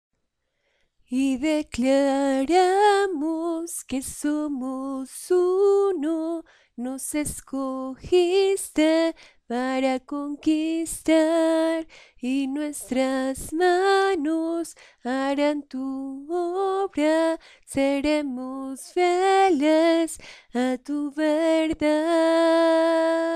Voz Mujer Coro